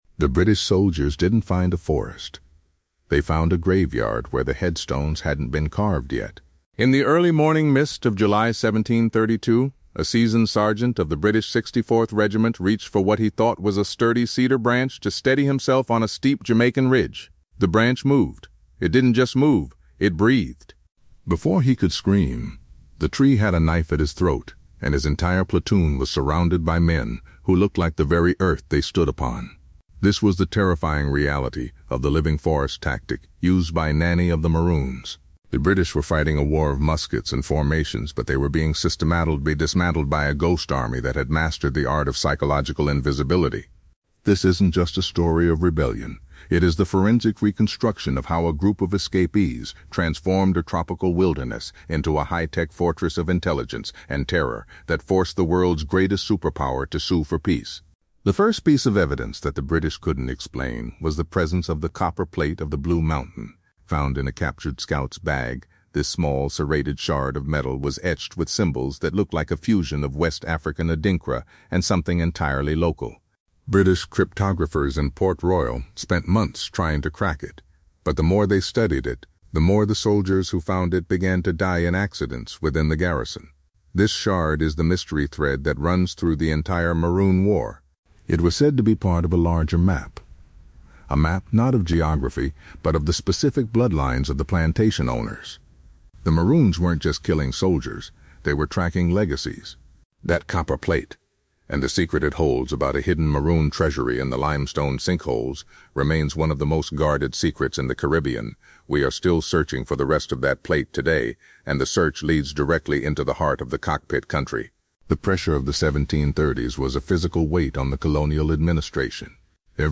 In this deep-dive documentary, we explore the true history of the Maroon Kingdoms—a sovereign nation that existed inside a colony.